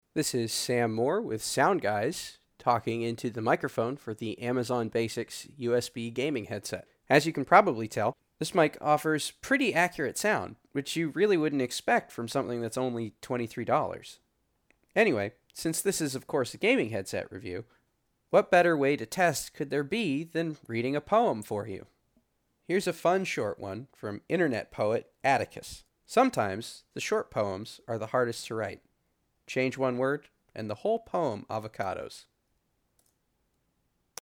AmazonBasics-USB-Mic-sample.mp3